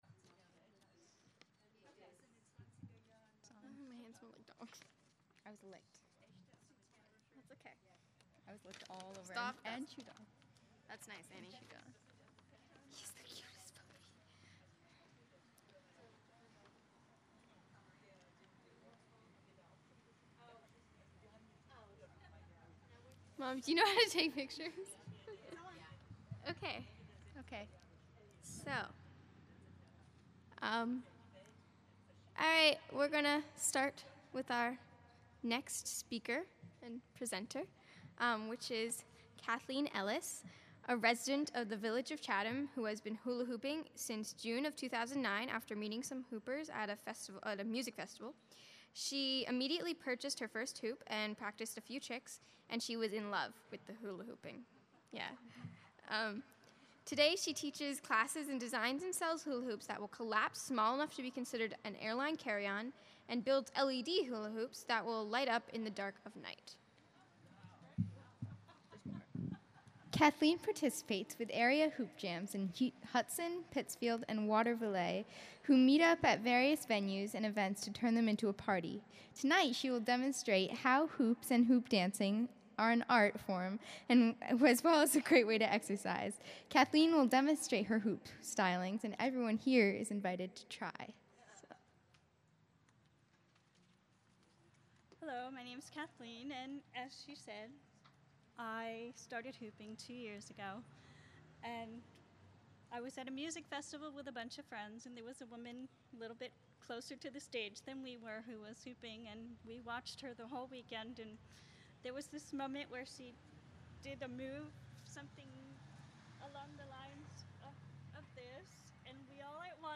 lecture series at Chatham Gazebo